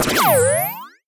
04999 analog game hitting laser bonus
analog bonus game hit laser retro sfx sound effect free sound royalty free Gaming